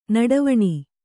♪ naḍavaṇi